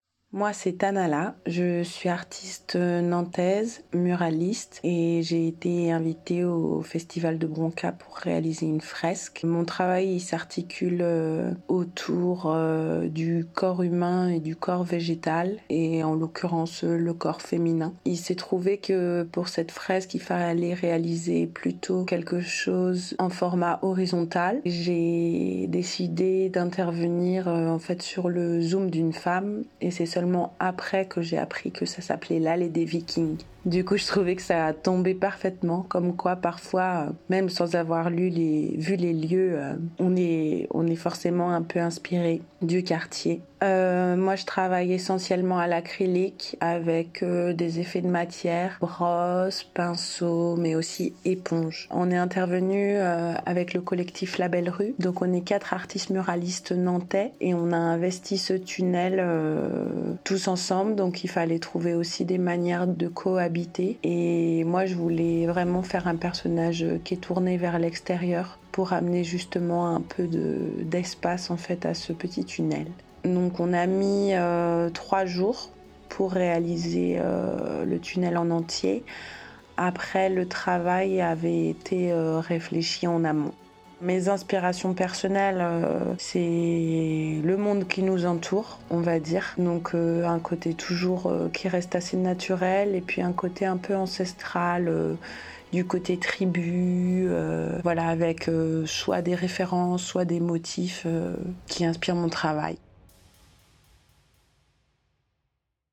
Interview
Le mieux placé pour en parler, c'est l'artiste.